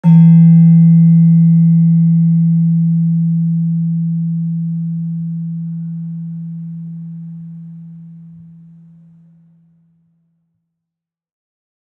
Gamelan
Gender-2-E2-f.wav